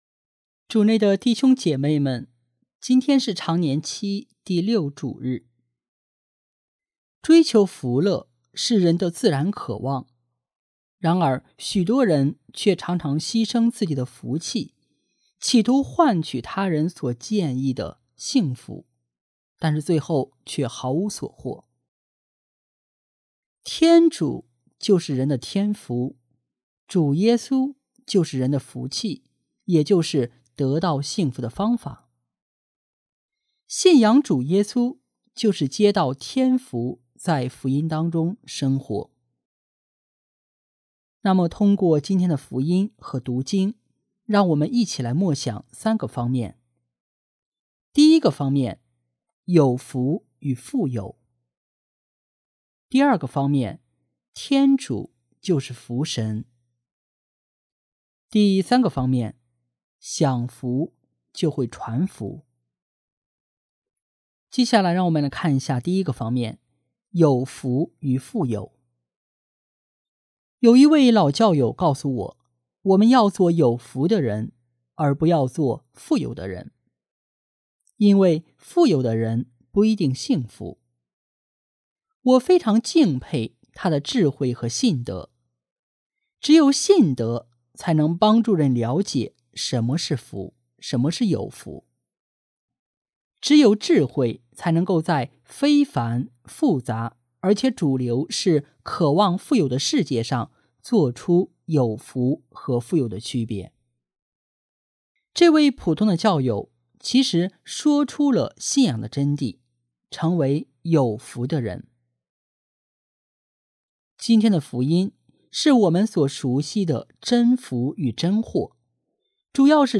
【主日证道】| 享福传福得天福（丙-常年期第6主日）